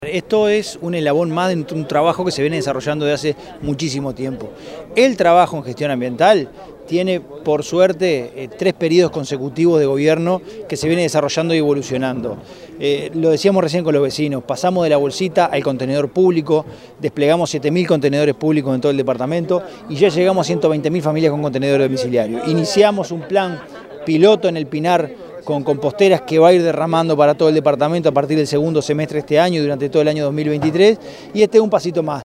francisco_legnani_-_secretario_general_intendencia_de_canelones.mp3